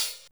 Hat (57).wav